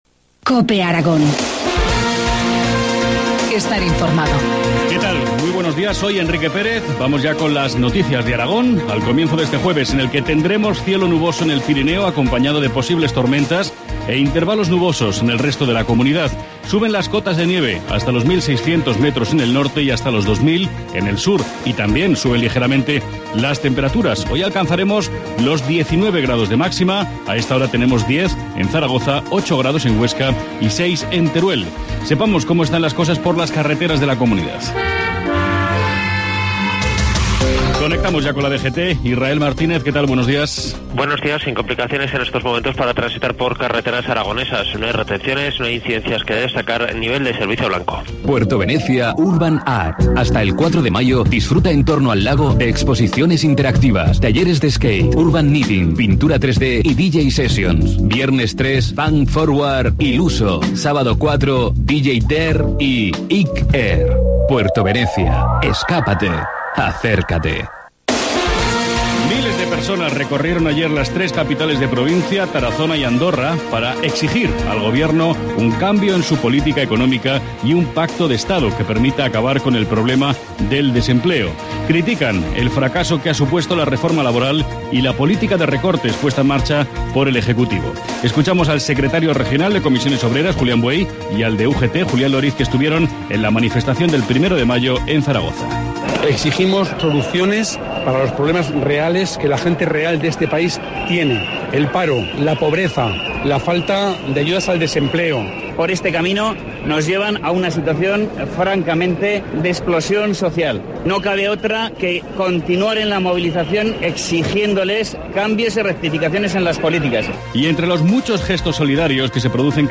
Informativo matinal, jueves 2 de mayo, 7.25 horas